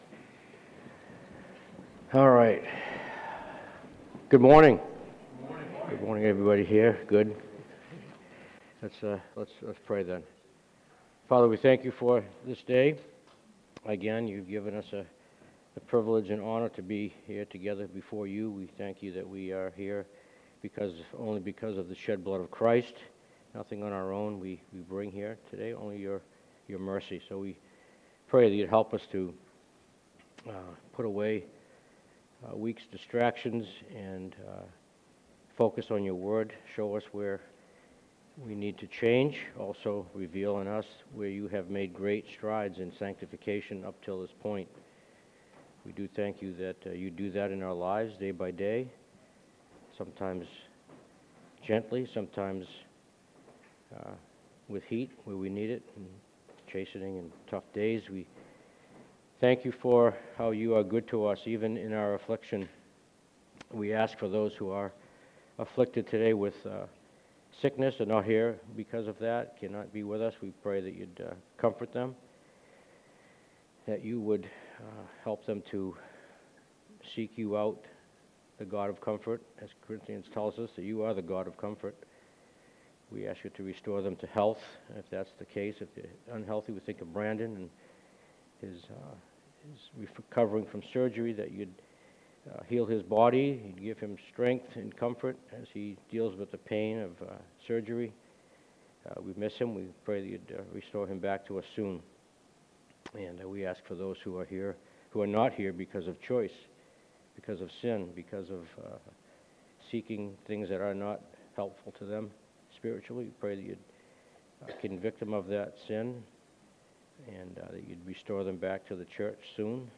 Adult Sunday School - Fellowship Bible Church